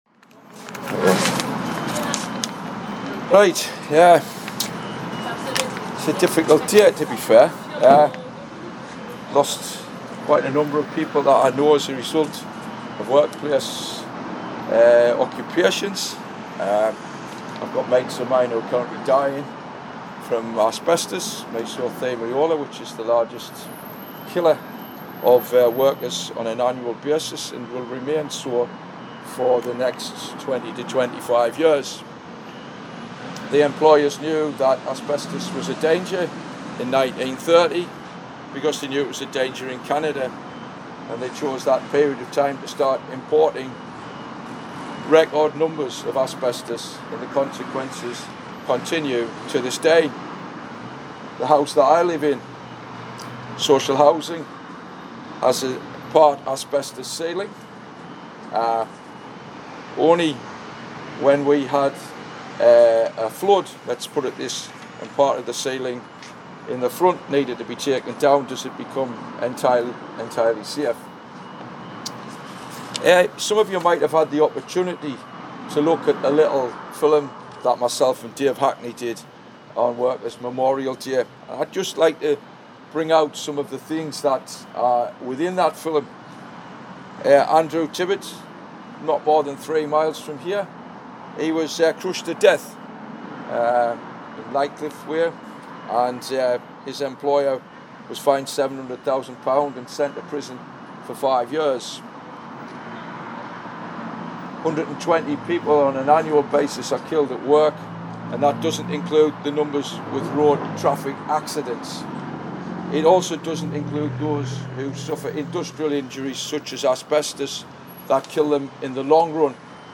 wmd-speech-2025.mp3